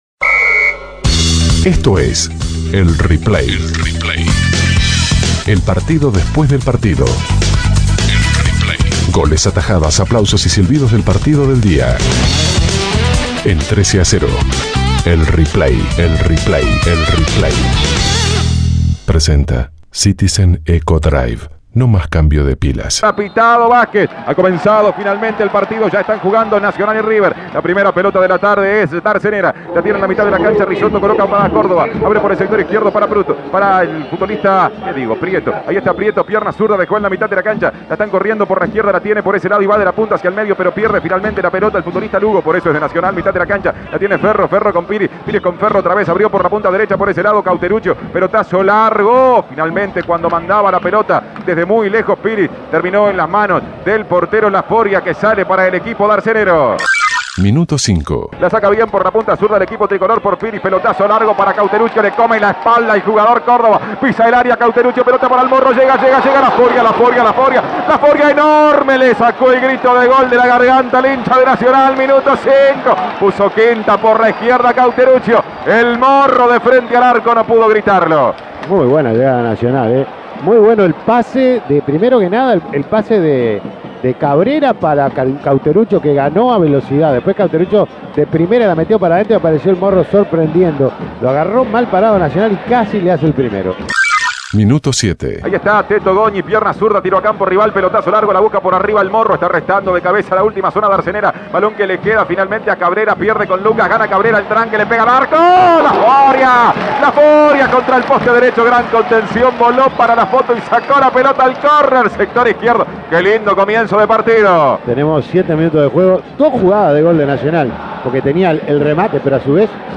Goles y comentarios Escuche el replay de Nacional - River Imprimir A- A A+ Nacional goleó por 6 a 1 a River en el Parque Central.